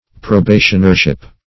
Search Result for " probationership" : The Collaborative International Dictionary of English v.0.48: Probationership \Pro*ba"tion*er*ship\, n. The state of being a probationer; novitiate.